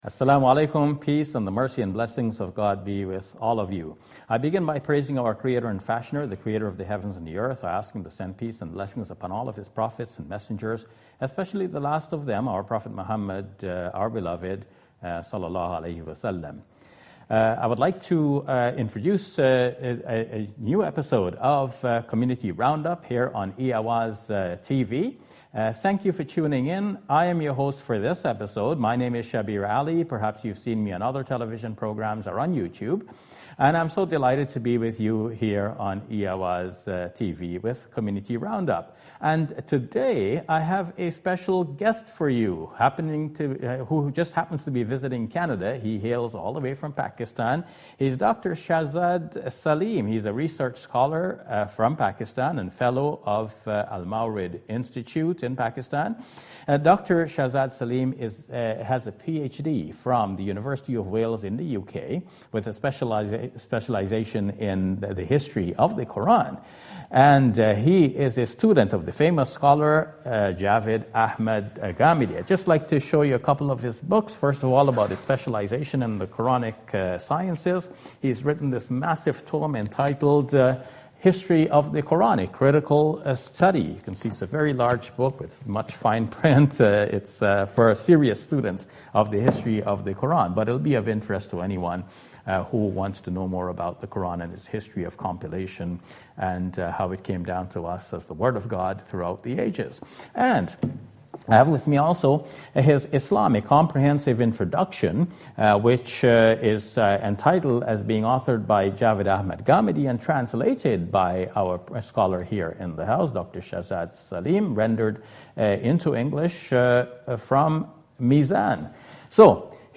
In the interview